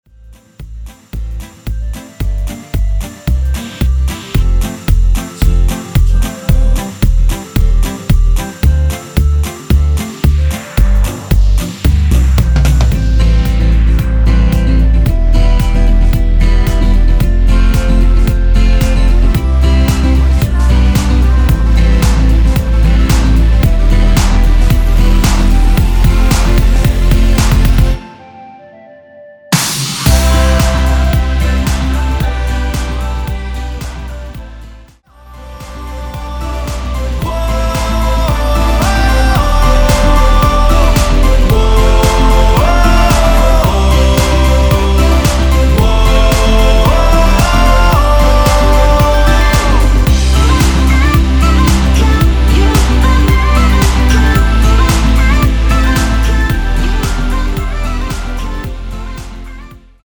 원키 멜로디와 코러스 포함된 MR입니다.
앞부분30초, 뒷부분30초씩 편집해서 올려 드리고 있습니다.